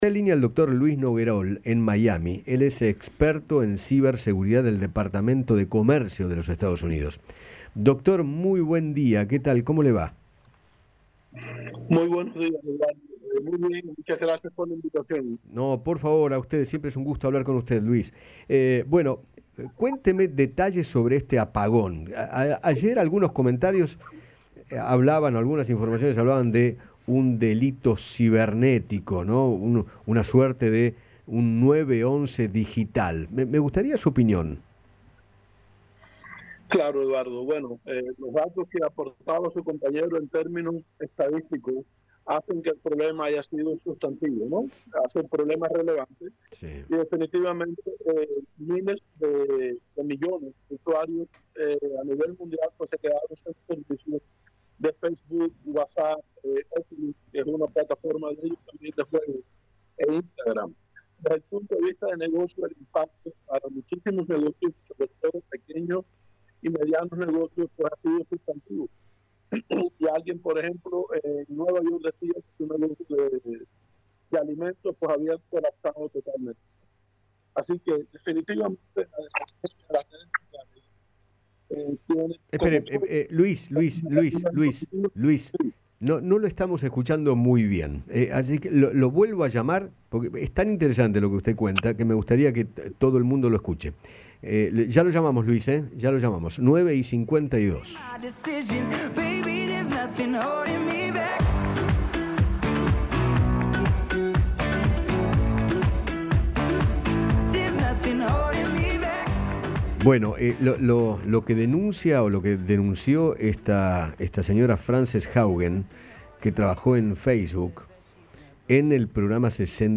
¿Por-que-se-cayeron-WhatsApp-Facebook-e-Instagram-Radio-R.mp3